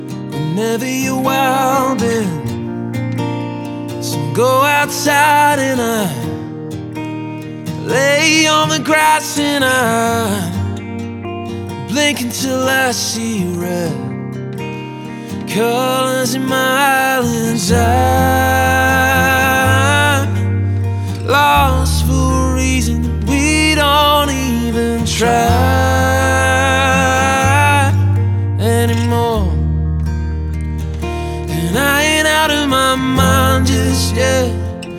Жанр: Иностранный рок / Рок / Инди
# Indie Rock